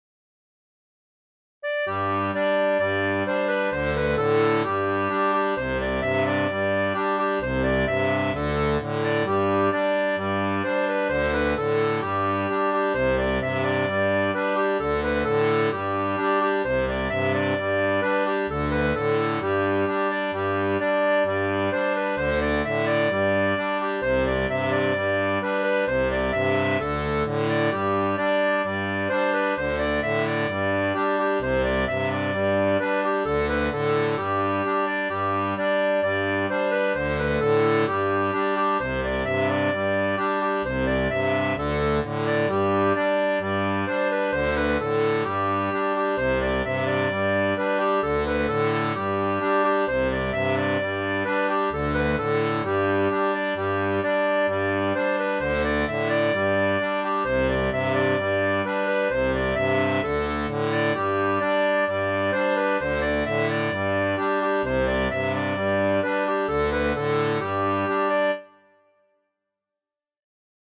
• une version pour accordéon diatonique à 2 rangs
Chanson française